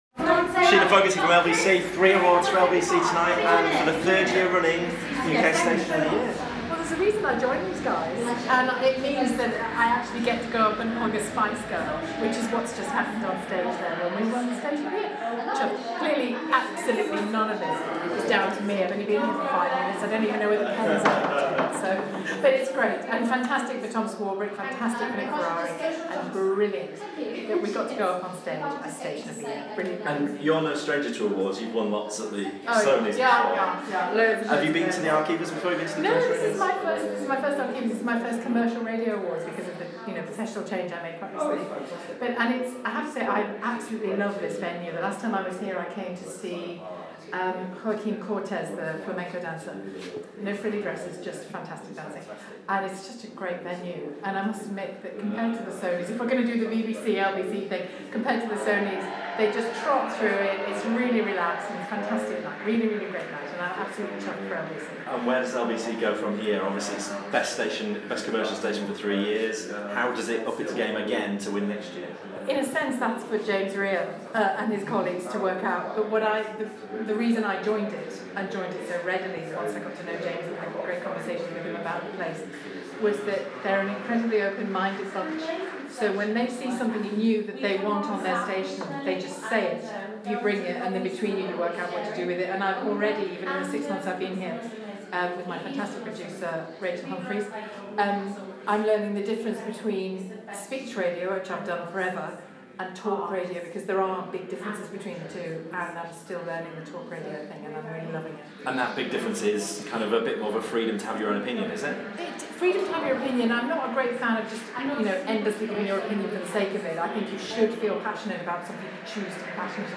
Shelagh Fogarty speaks to us after LBC named station of the year